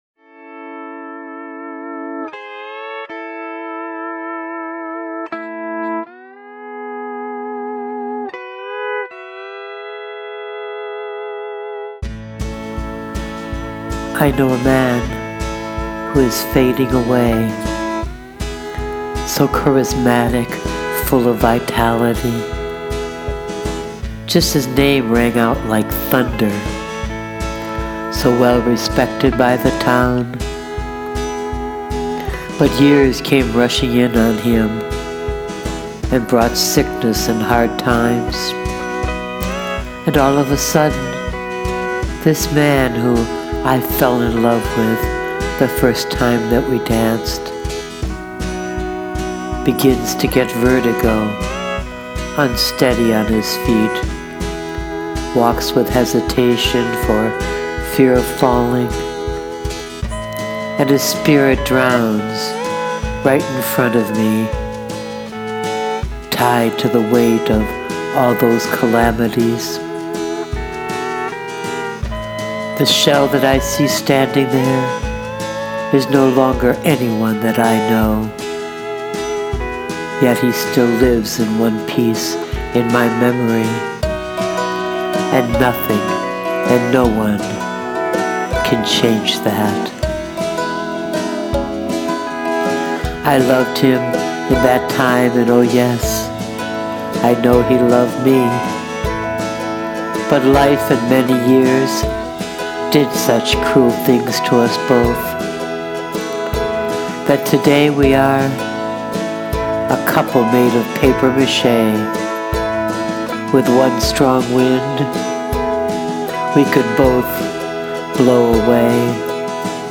The music in that post, reminded me of someone from my real life (that I have written about here before) and all of a sudden, I was inspired to try and write some country western music in Garage Band and make a real-life follow-up of that post…
La musica es un acompañamiento ideal para hacer resaltar tu voz.